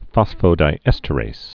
(fŏsfō-dī-ĕstə-rās, -rāz)